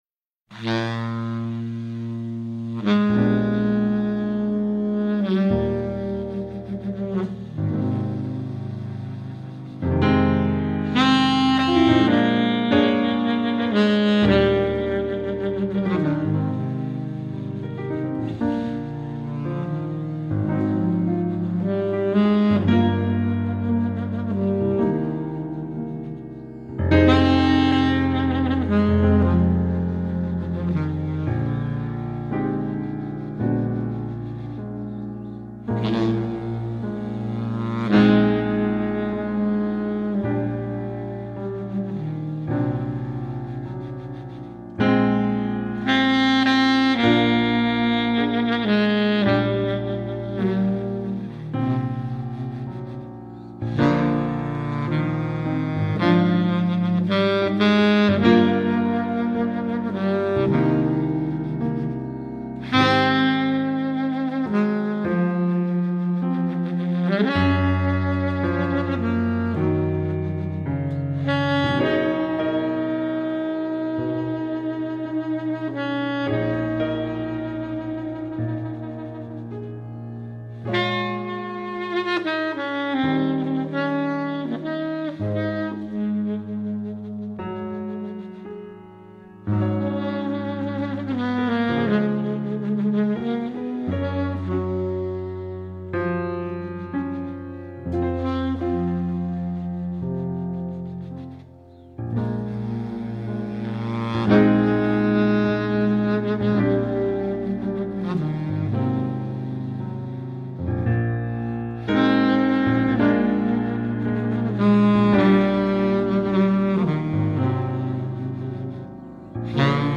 Free Jazz
bar sax